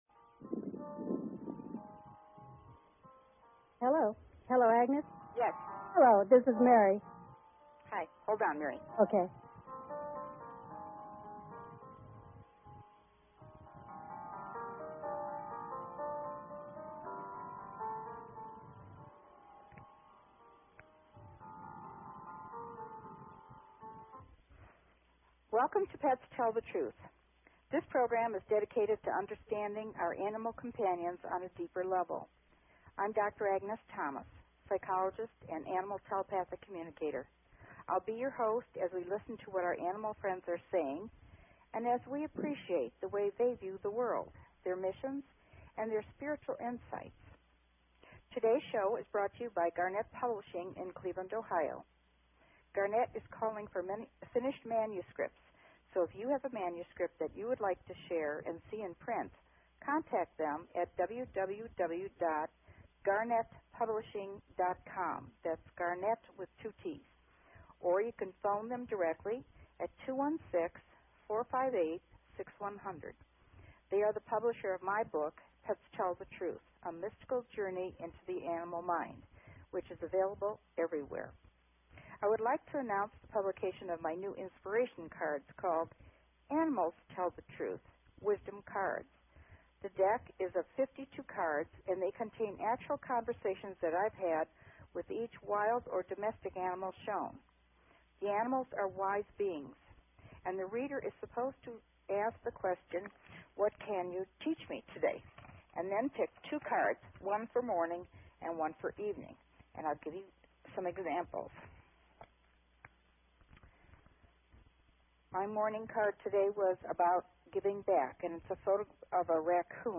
Talk Show Episode, Audio Podcast, Pets_Tell_The_Truth and Courtesy of BBS Radio on , show guests , about , categorized as